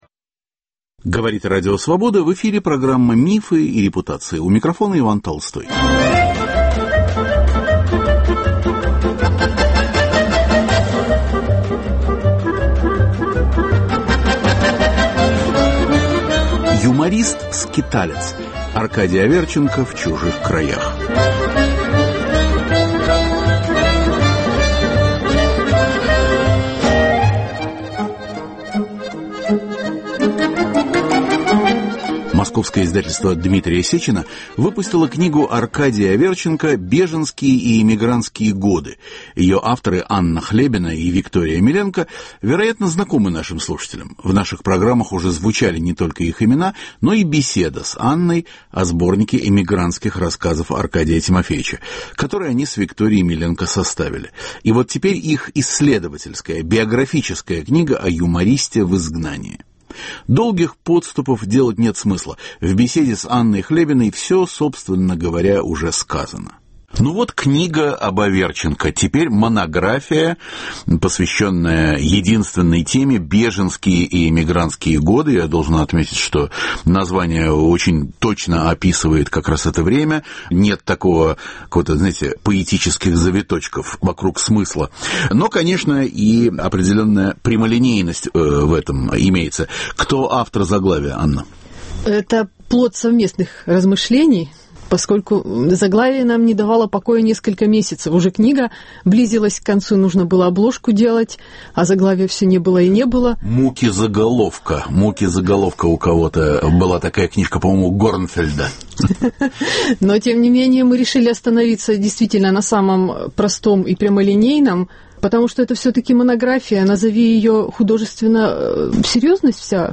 Юморист-скиталец. Аркадий Аверченко в чужих краях. Беседа